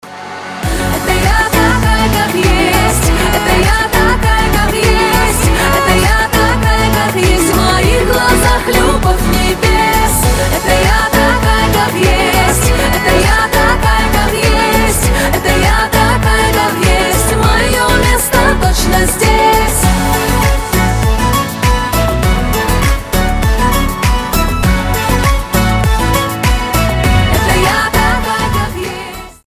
• Качество: 320, Stereo
поп
позитивные